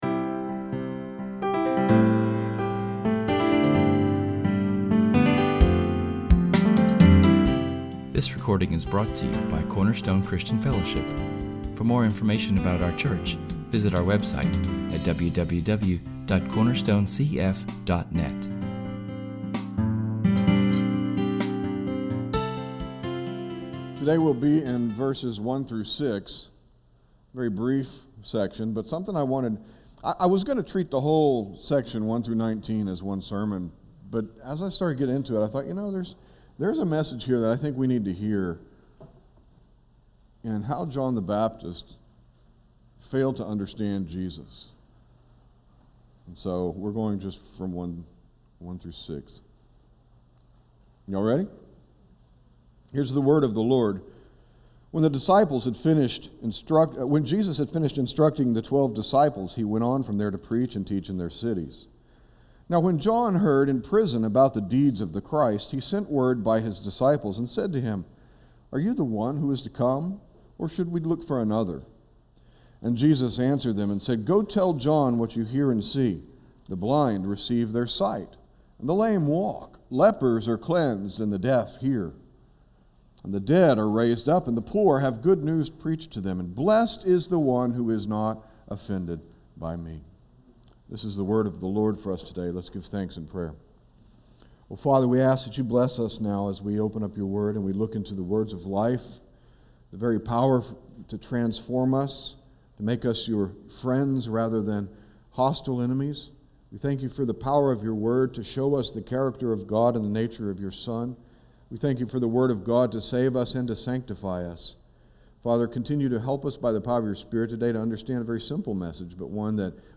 Our sermon continues the study of the Gospel of Matthew and is taken from [esvignore]Matthew 11:1-6[/esvignore]. We will look at doubt and the fruit of doubt, and will be reminded that there is a solid foundation on which to stand.